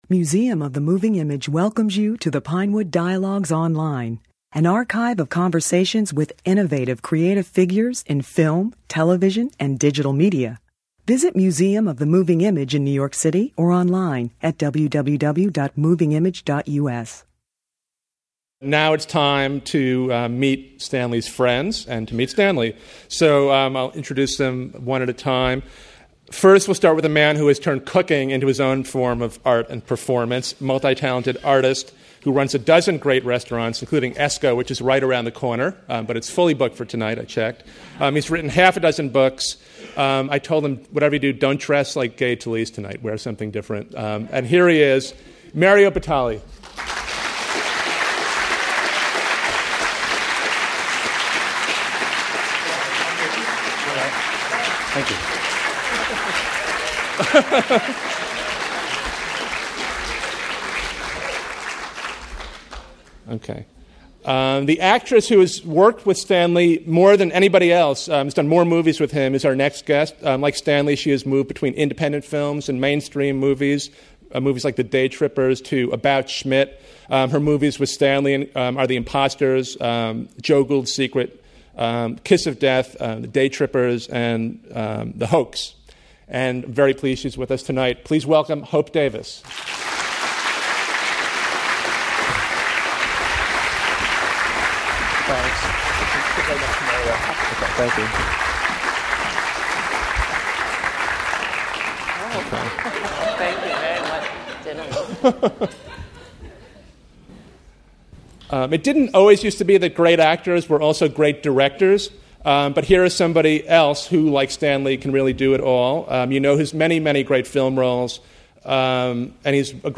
In this informal conversation, Tucci discusses his art and craft with some friends: the chef and restaurateur Mario Batali ; actress Hope Davis , who has worked with Tucci on five films; actress Natasha Richardson ; and actor Steve Buscemi , who has formed a new company with Tucci, Olive Productions. The conversation was moderated by author Gay Talese .